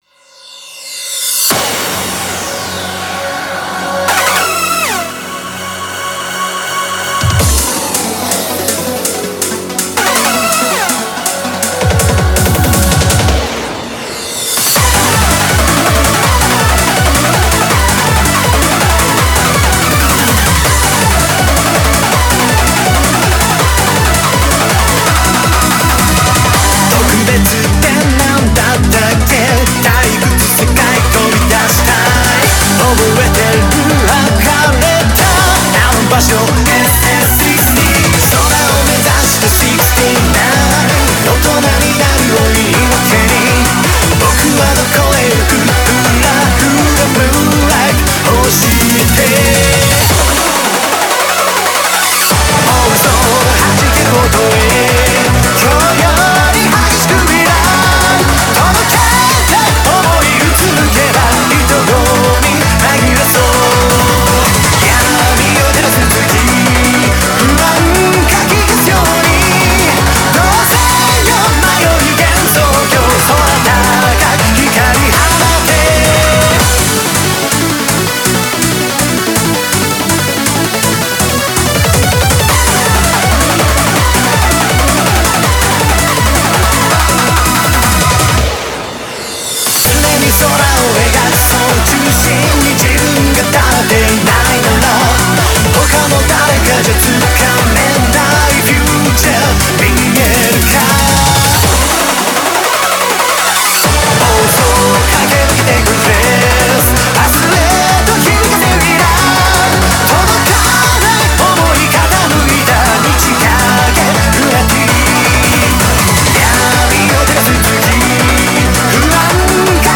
BPM163
Comments[TOUHOU EUROBEAT]